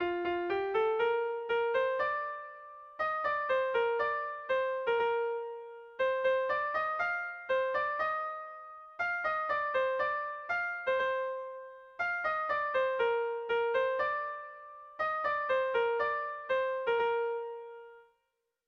Erromantzea
ABDEE2B